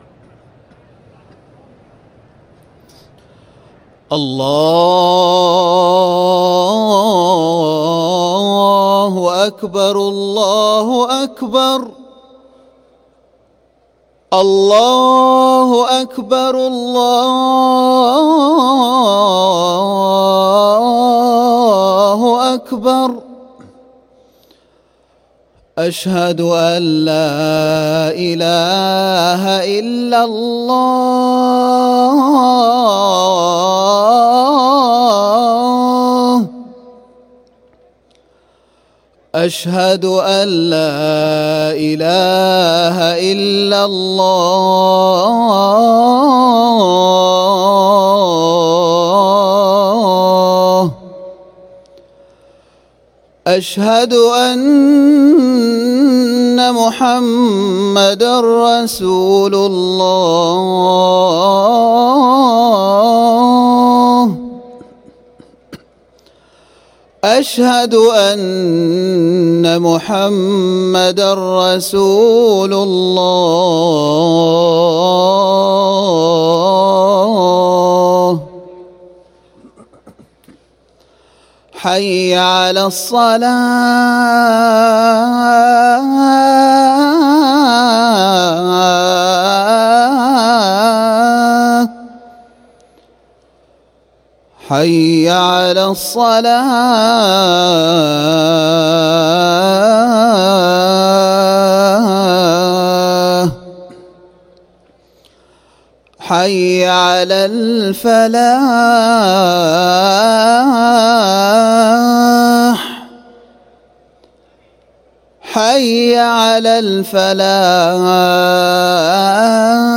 أذان الظهر للمؤذن أحمد خوجة الاثنين 15 ذو الحجة 1444هـ > ١٤٤٤ 🕋 > ركن الأذان 🕋 > المزيد - تلاوات الحرمين